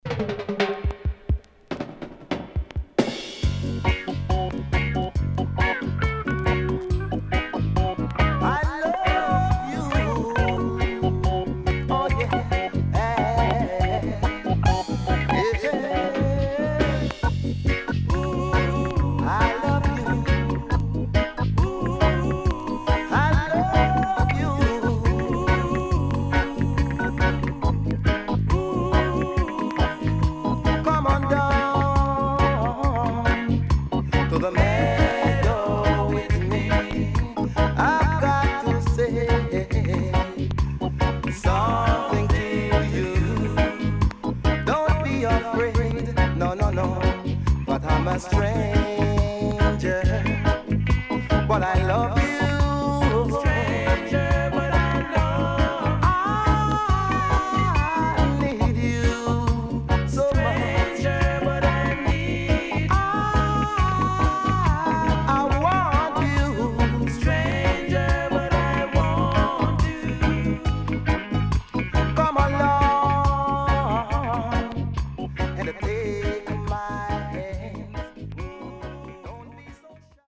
ソウルフルで爽やかなStepper Reggae名盤です。